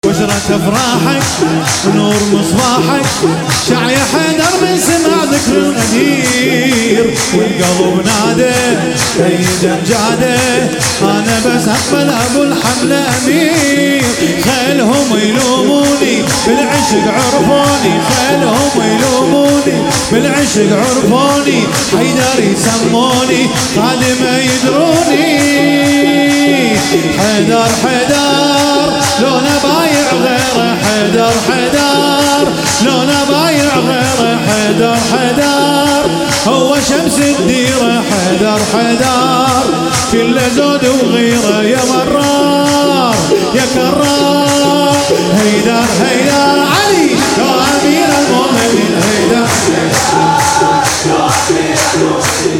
ولادت حضرت معصومه (س)